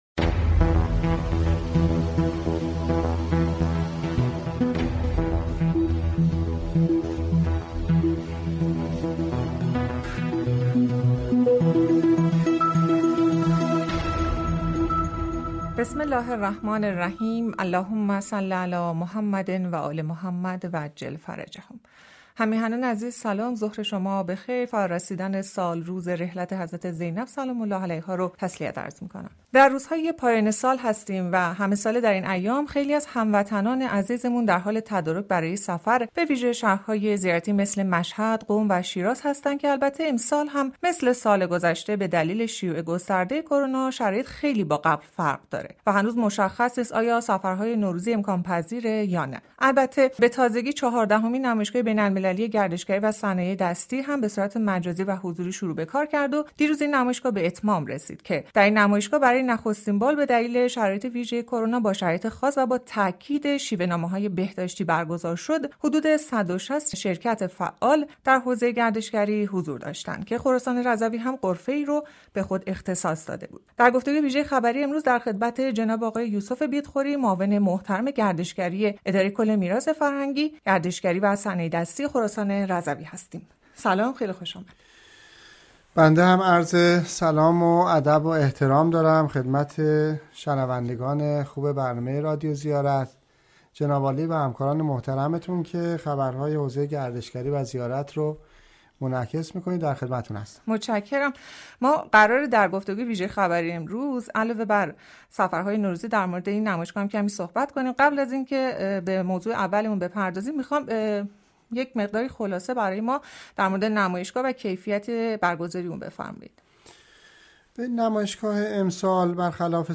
به گزارش خبر رادیو زیارت ،کرونا و تاثیرات آن بر سفرهای نوروزی به ویژه در شهر های زیارتی عنوان گفتگوی ویژه خبری رادیو زیارت بود که این موضوع با حضور مهمان برنامه در استودیو خبر به آن پرداخته شد.